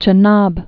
(chə-näb)